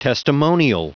Prononciation du mot testimonial en anglais (fichier audio)
Prononciation du mot : testimonial